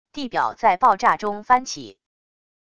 地表在爆炸中翻起wav音频